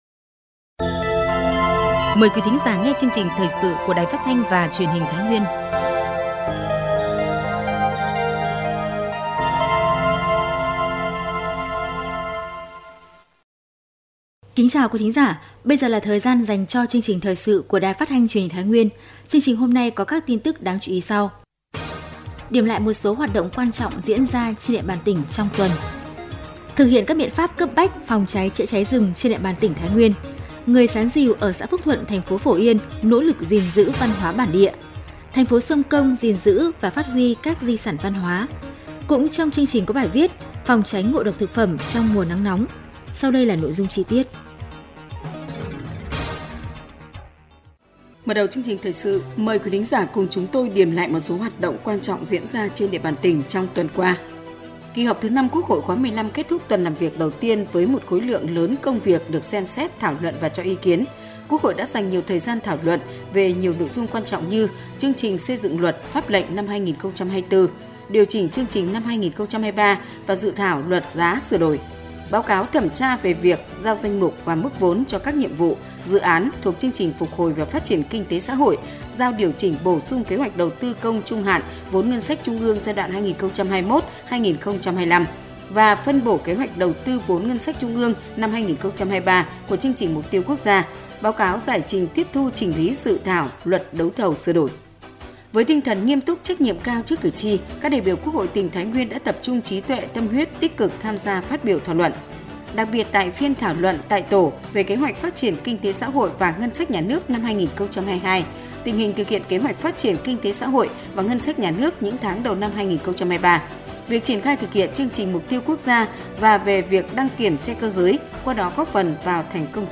Thời sự